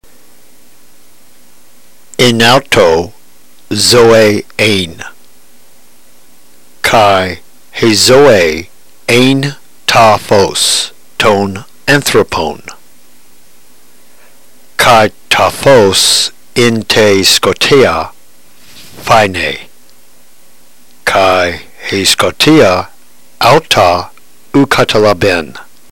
Notice that the words not accented are glided into the following word without hesitation.